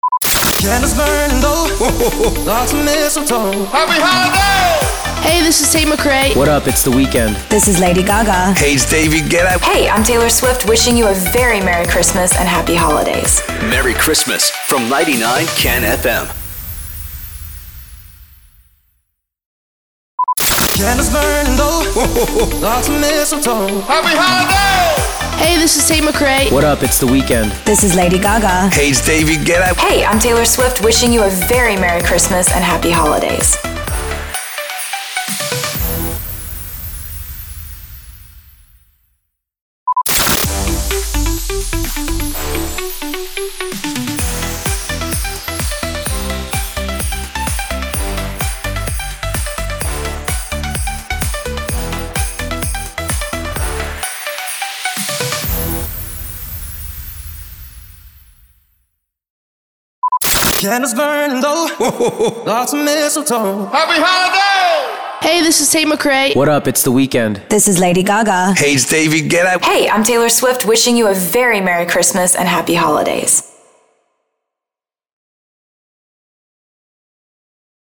665 – SWEEPER – HOLIDAY ARTIST MONTAGE
665-SWEEPER-HOLIDAY-ARTIST-MONTAGE.mp3